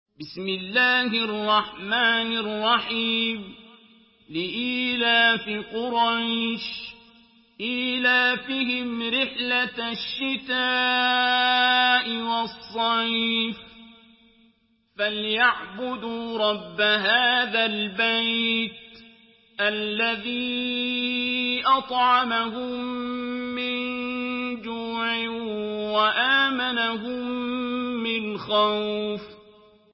Surah قريش MP3 by عبد الباسط عبد الصمد in حفص عن عاصم narration.
مرتل